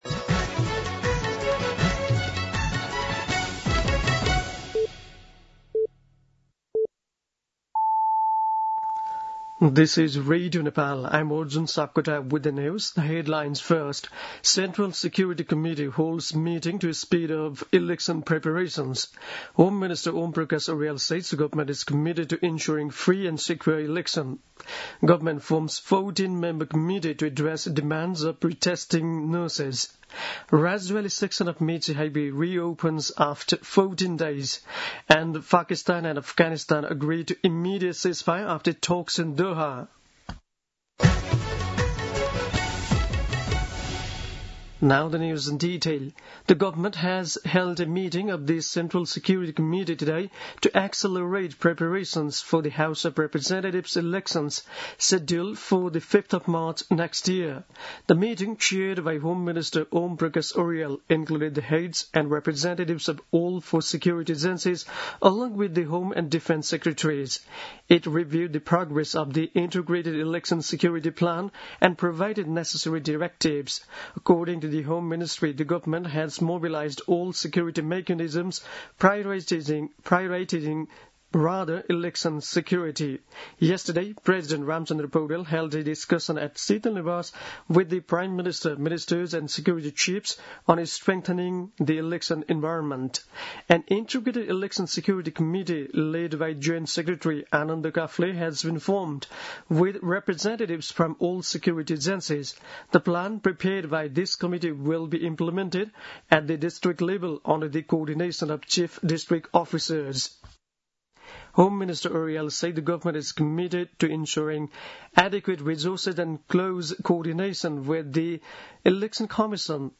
दिउँसो २ बजेको अङ्ग्रेजी समाचार : १८ पुष , २०२६
2pm-English-Nepali-News.mp3